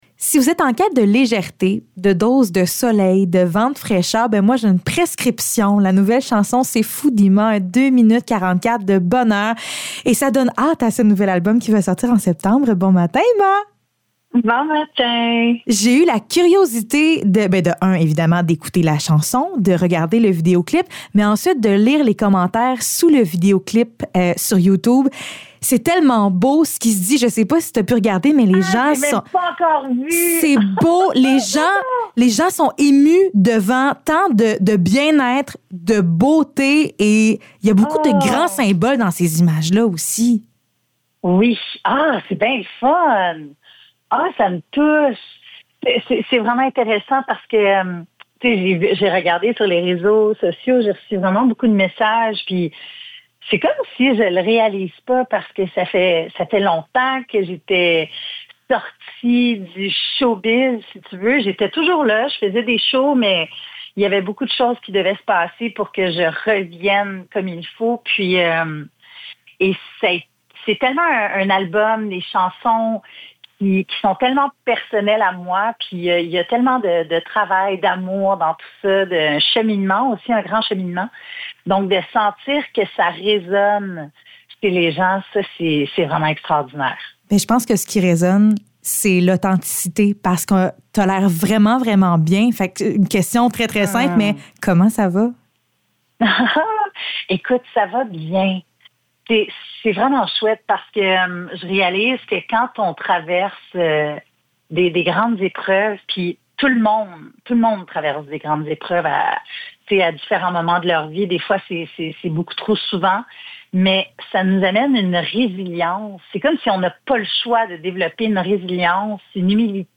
Entrevue avec IMA